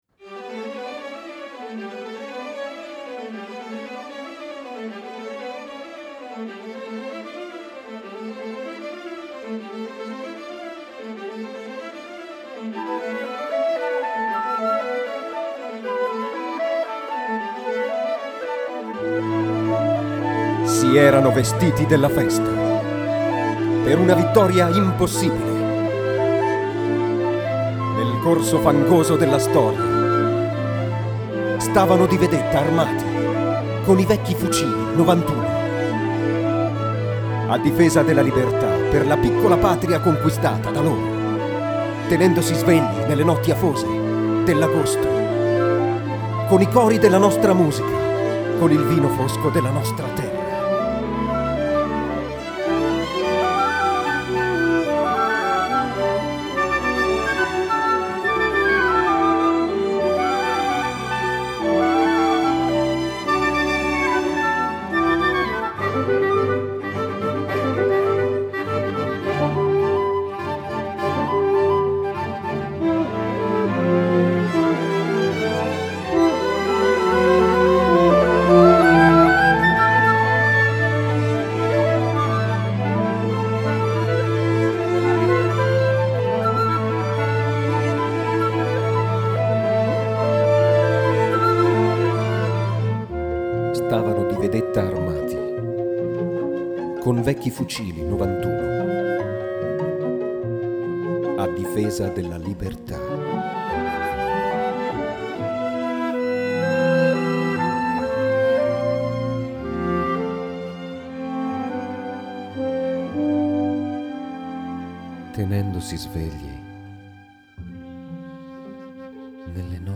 voce recitante e canto
Prima esecuzione assoluta.